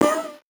Index of /musicradar/8-bit-bonanza-samples/VocoBit Hits
CS_VocoBitC_Hit-01.wav